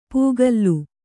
♪ pūgallu